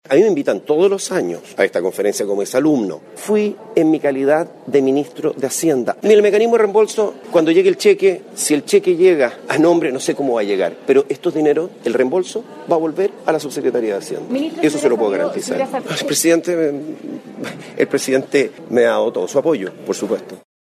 Dijo estar tranquilo, pero contestó nervioso y en especial consultado sobre cómo operará el mecanismo para reembolsar el dinero del viaje. El ministro Felipe Larraín acusó por segunda vez una política pequeña desde la oposición y aseguró que recibió todo el respaldo del mandatario.
617-cuna-hacienda-felipelarrain.mp3